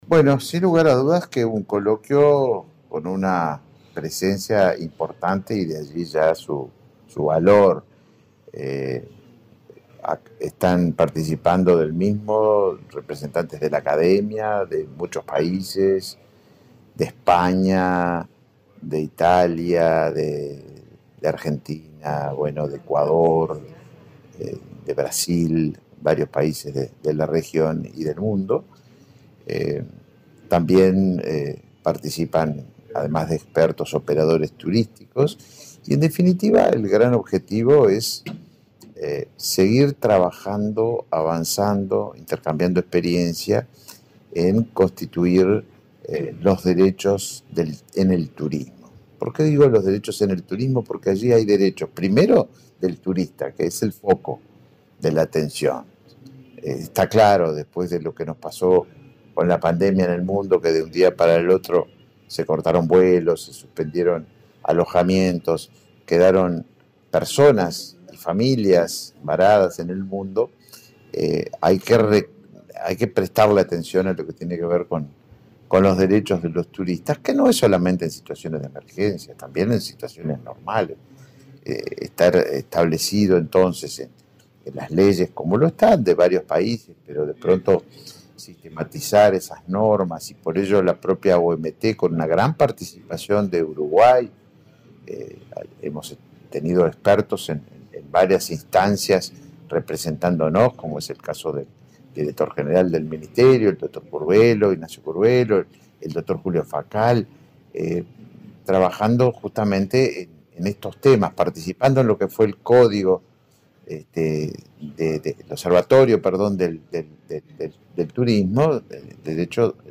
Declaraciones del ministro de Turismo, Tabaré Viera
Declaraciones del ministro de Turismo, Tabaré Viera 08/11/2023 Compartir Facebook X Copiar enlace WhatsApp LinkedIn Este miércoles 8 en Montevideo, el ministro de Turismo, Tabaré Viera, participó de la apertura del primer Coloquio Internacional de Derecho y Sostenibilidad en Turismo. Luego, dialogó con la prensa.